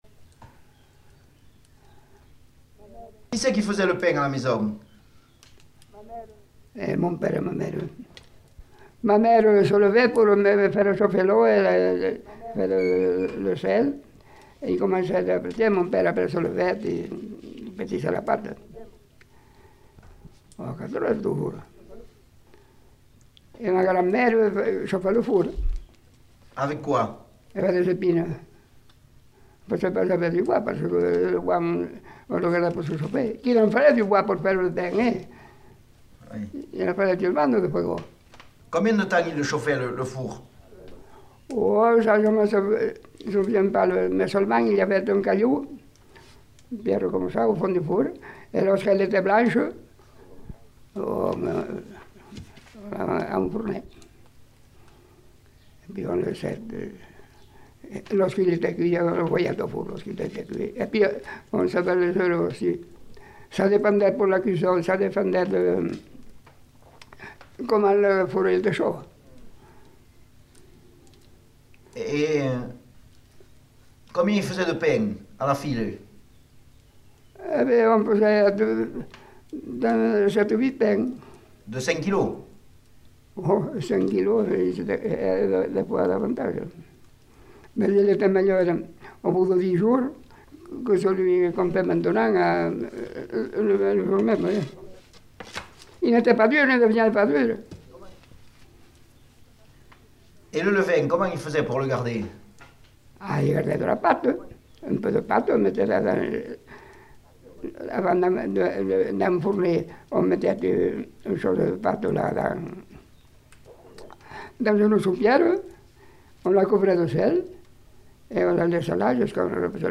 Lieu : Pompiac
Genre : témoignage thématique